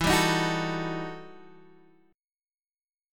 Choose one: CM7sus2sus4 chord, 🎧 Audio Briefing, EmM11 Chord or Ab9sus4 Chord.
EmM11 Chord